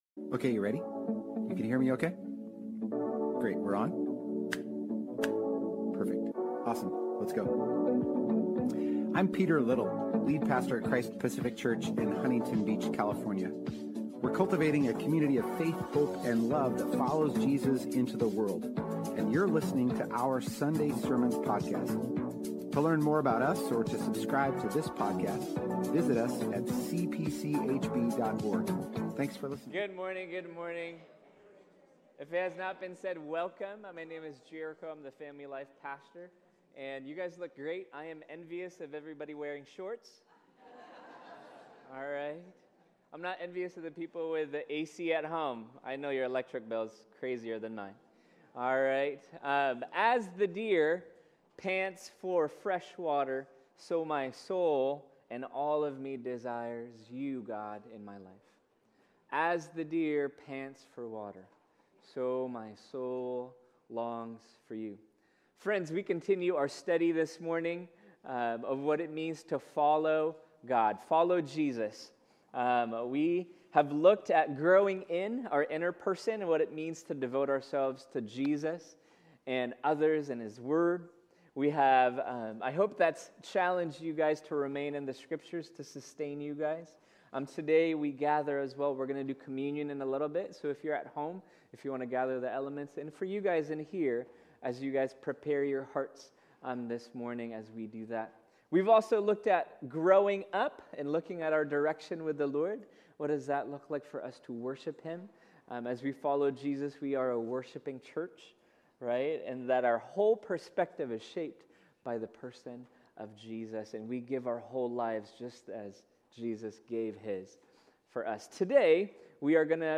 Join us today as we conclude our current sermon series.